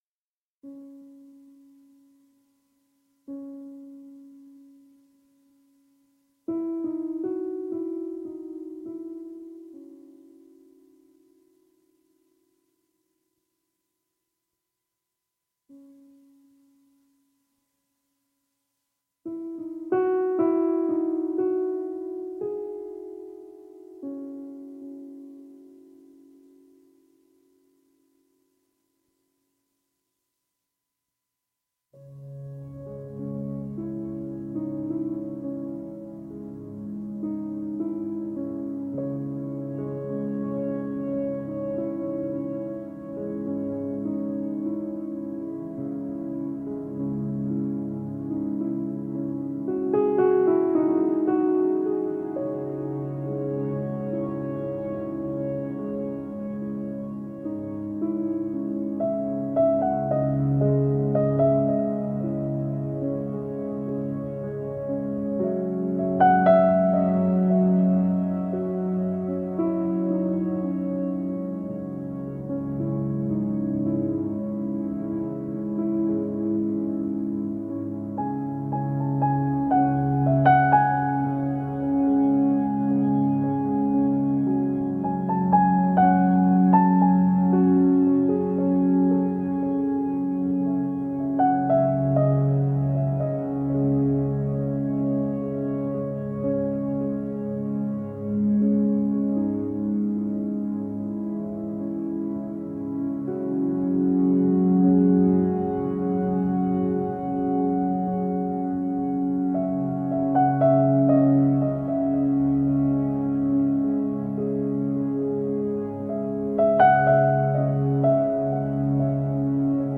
Minimalist piano with slashes of ambient electronics.